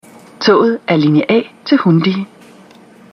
efter opdatering af togets computer sommer 2012, 4. generations S-tog